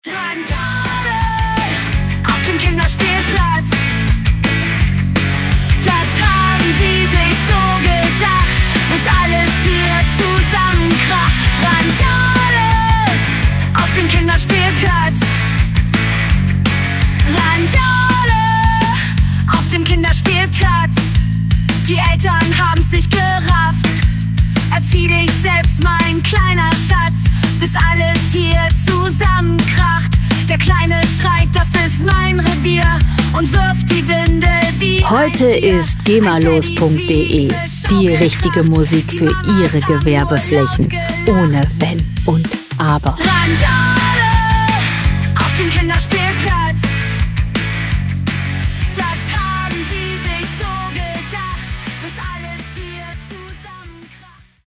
Musikstil: Deutschpunk
Tempo: 167 bpm
Tonart: A-Dur
Charakter: trozig, wild
Instrumentierung: Sänger, E-Gitarre, E-Bass, Drums